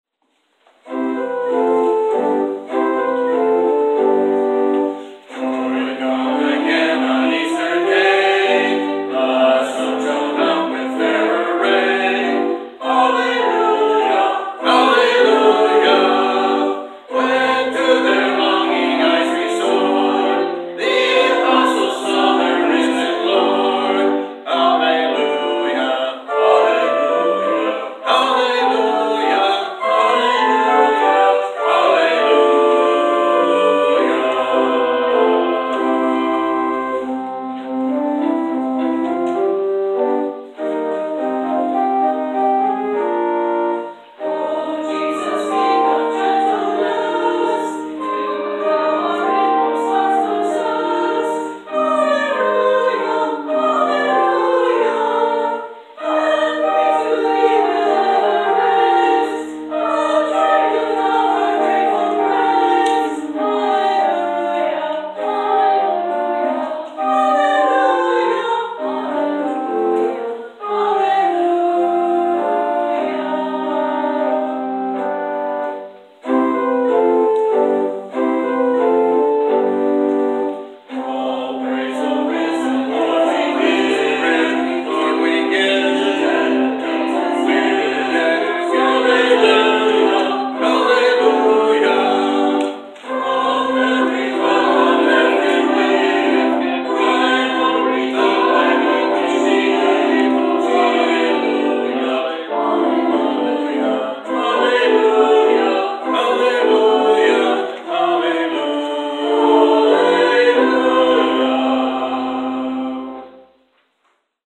Choir Anthem: